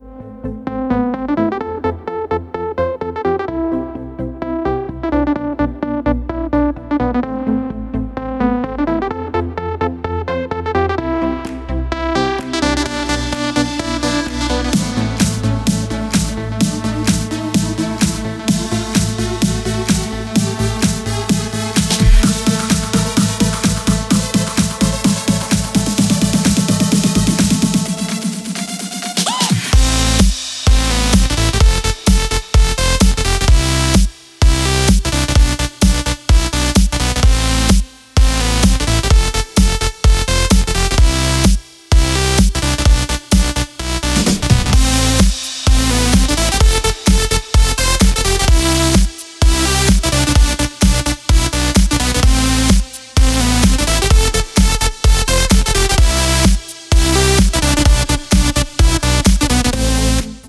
• Качество: 128, Stereo
громкие
мелодичные
веселые
dance
Electronic
EDM
нарастающие
progressive house